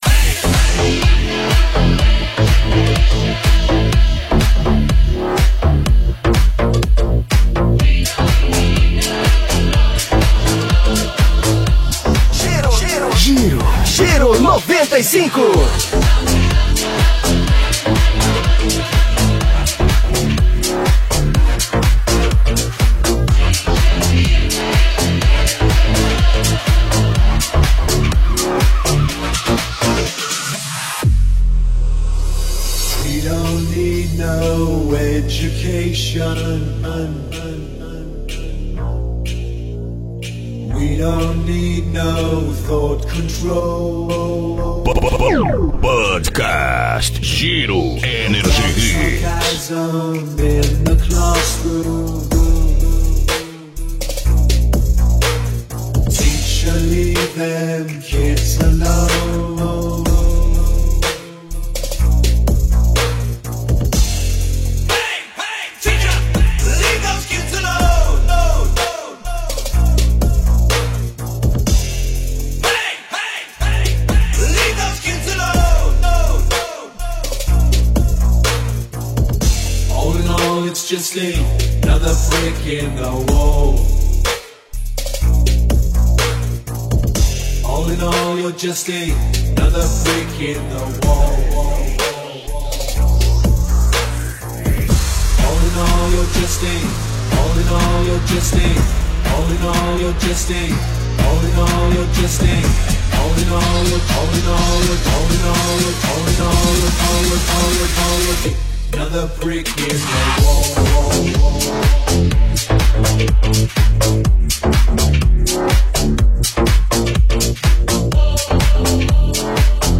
Estilo(s): Deep-House Electro-House